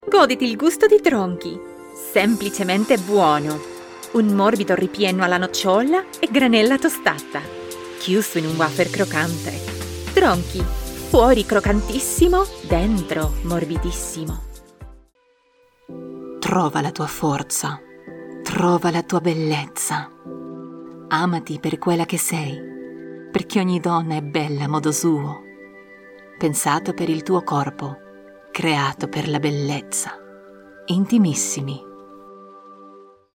Female
Italian Commercial Reel
0630Italian_Commercial_Reel.mp3